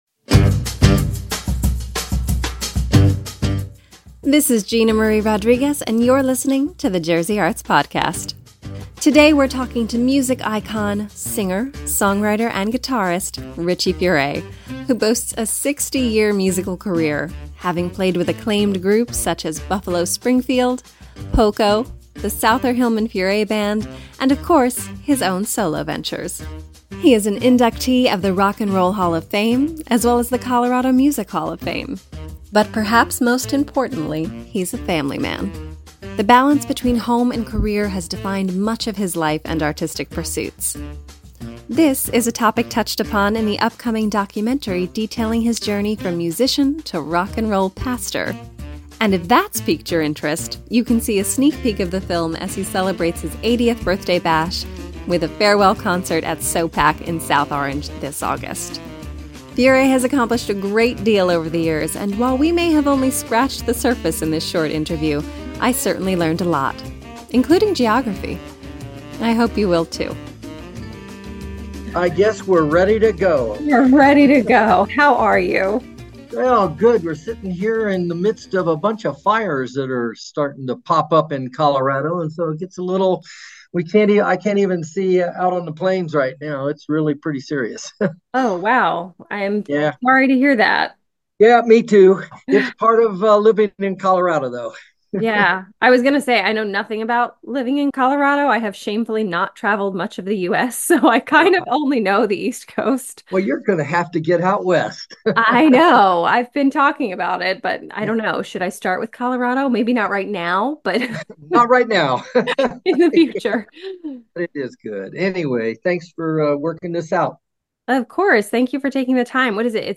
Jersey Arts talks to music icon - singer, songwriter, and guitarist Richie Furay, who boasts a 60 year musical career, having played with acclaimed groups such as Buffalo Springfield, Poco, the Souther-Hillman-Furay Band and of course, his own solo ventures.